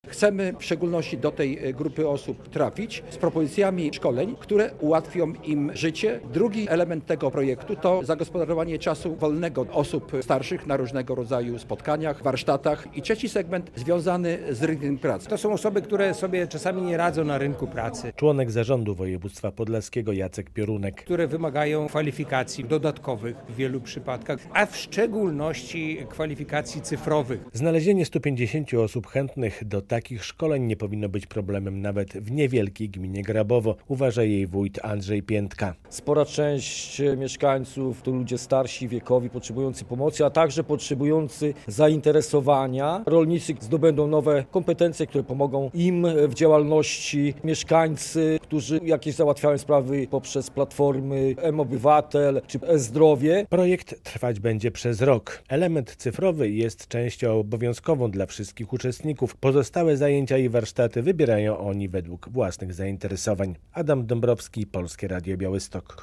LOWE w czterech łomżyńskich gminach - relacja